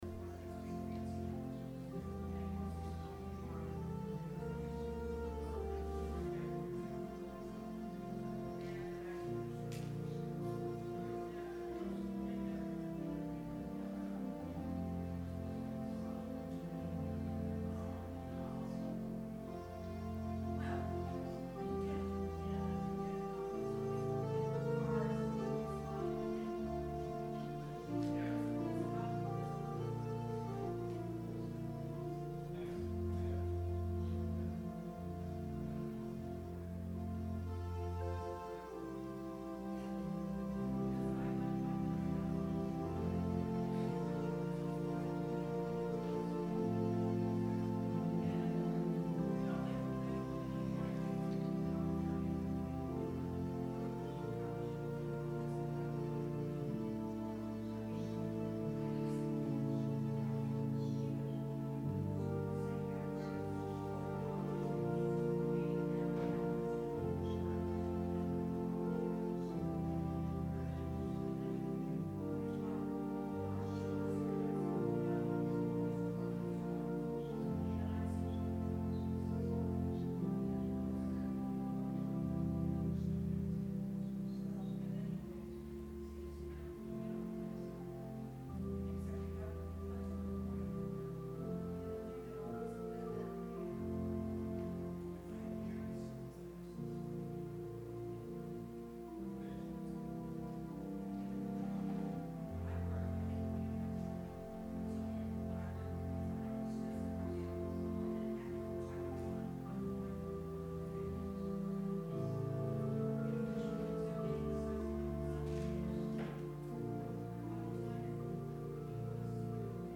Sermon – August 18, 2019